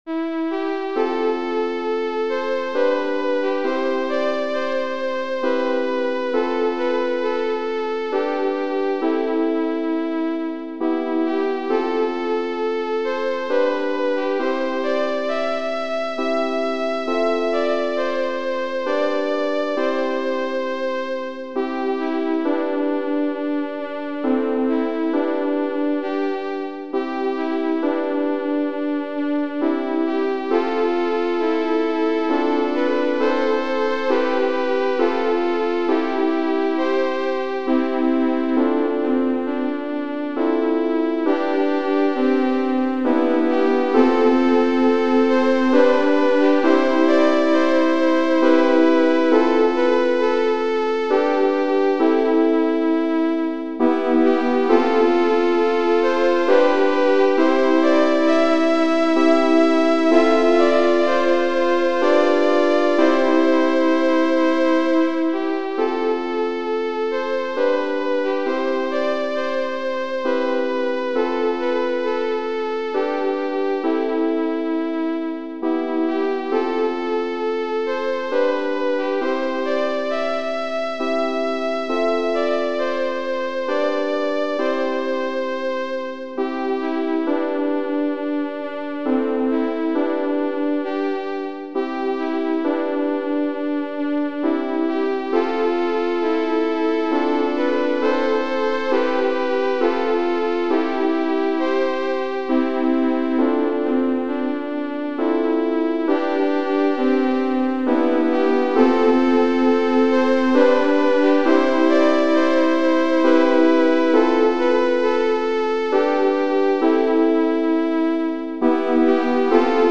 Genere: Sociali e Patriottiche